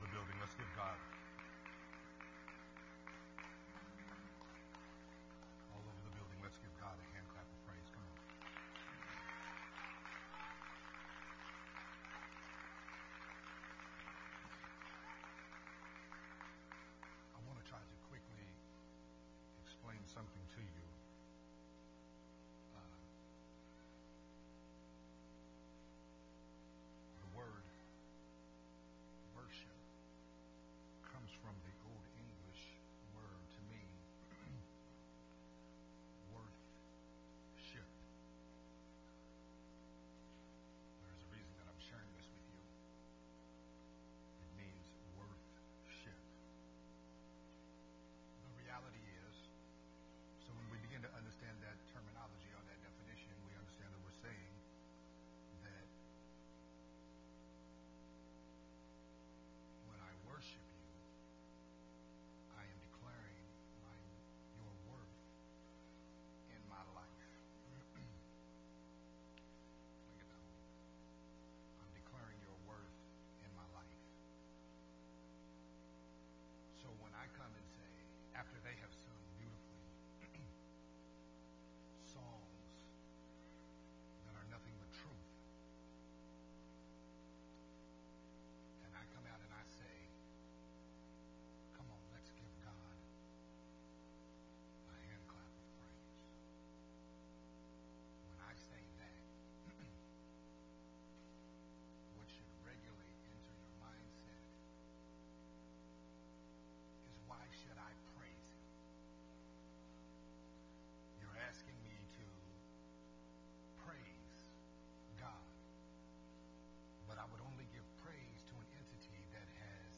recorded at Unity Worship Center on November 21, 2021.
sermon